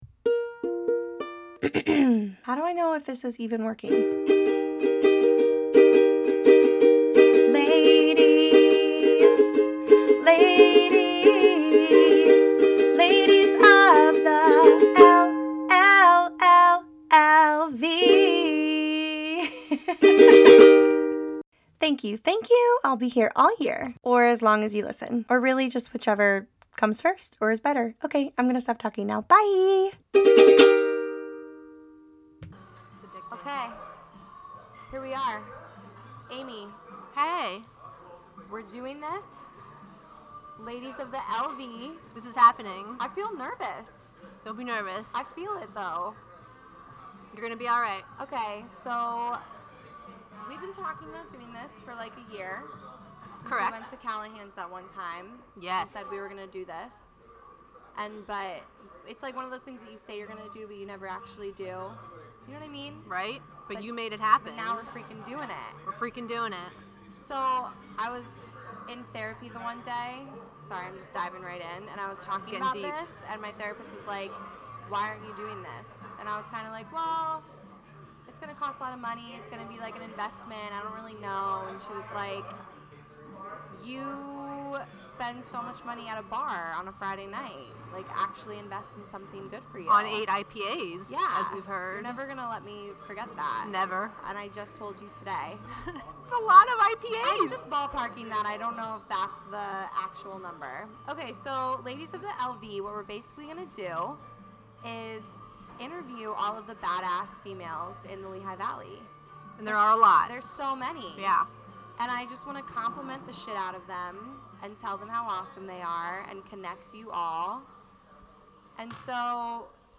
interview their very first badass lady at The Hamilton